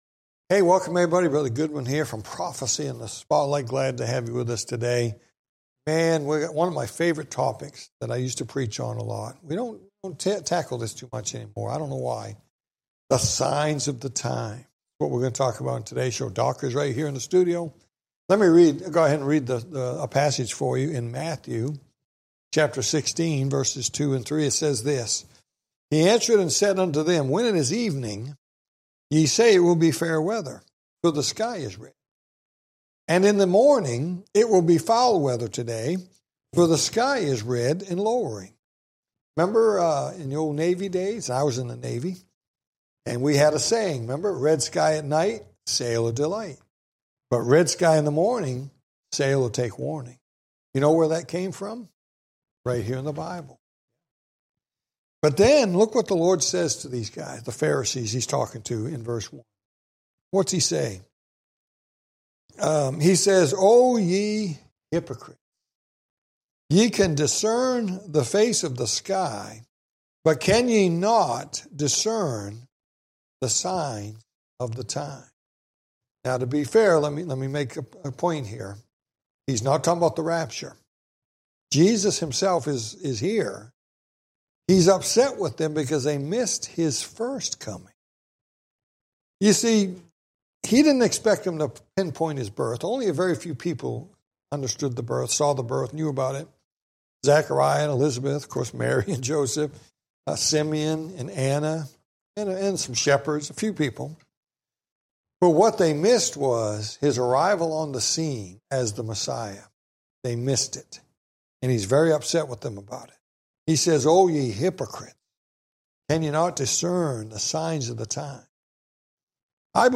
Talk Show Episode, Audio Podcast, Prophecy In The Spotlight and Signs Of The Times on , show guests , about Signs of the times, categorized as History,News,Politics & Government,Religion,Society and Culture,Theory & Conspiracy